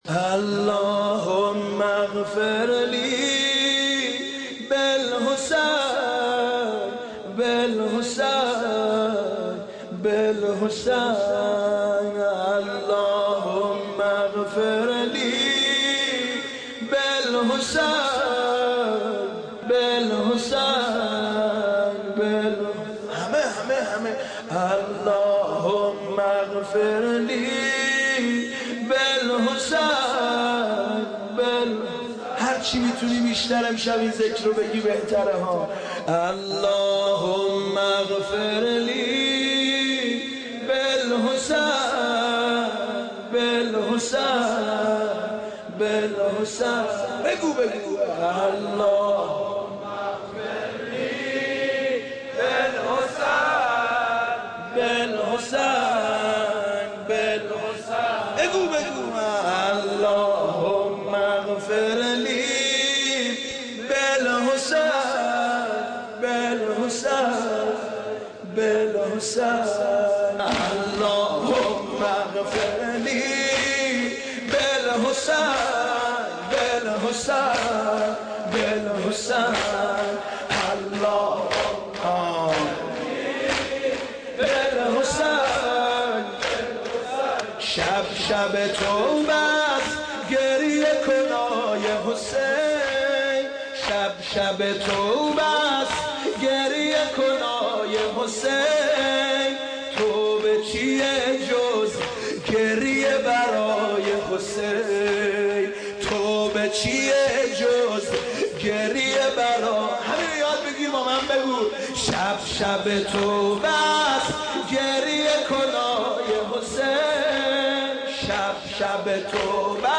نوحه مداحی دیدگاه‌ها